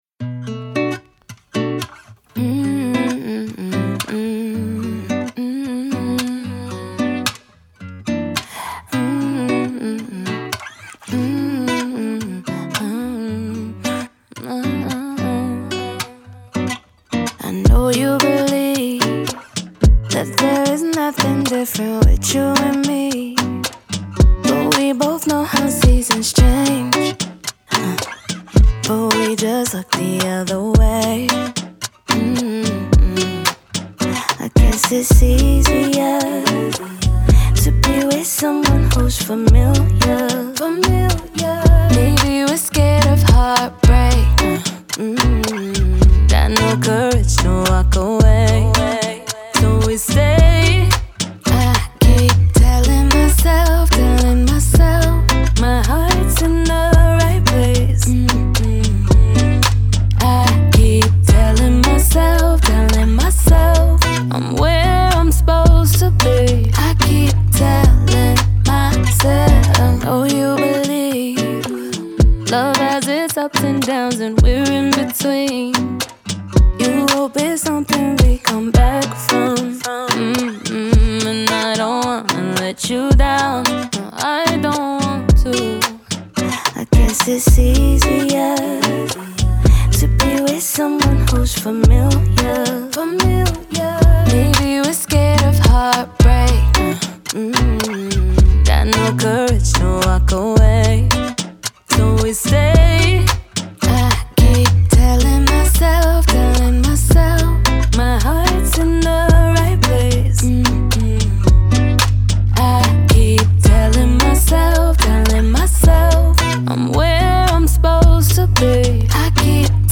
R&B, Acoustic
B min